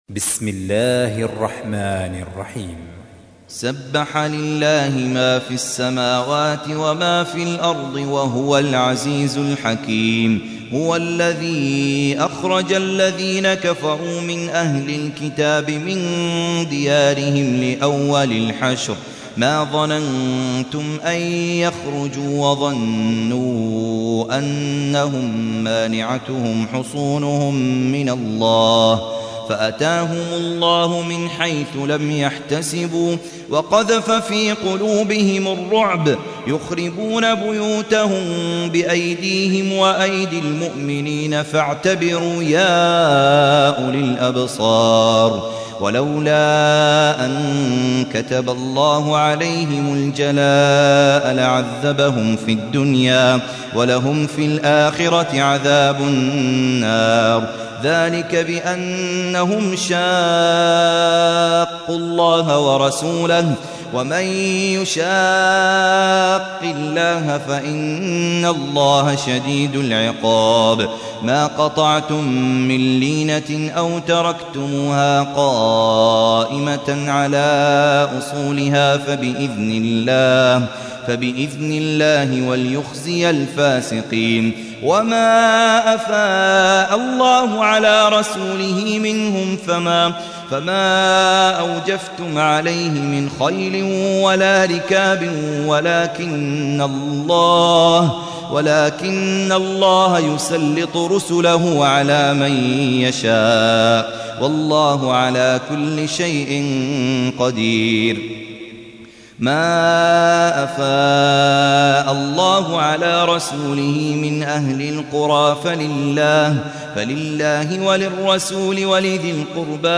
تحميل : 59. سورة الحشر / القارئ خالد عبد الكافي / القرآن الكريم / موقع يا حسين